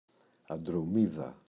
αντρομίδα, η [andro’miða] – ΔΠΗ